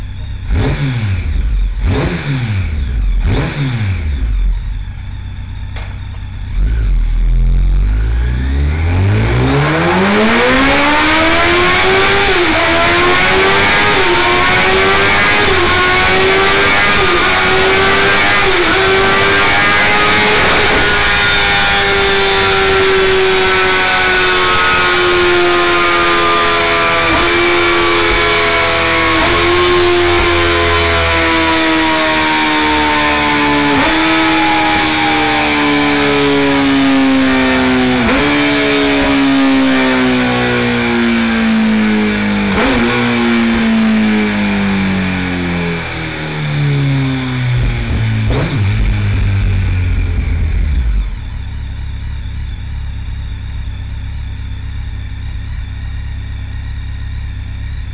exhaust sound clips?!?!
That area p sounds pissed.
zx10r_04.mp3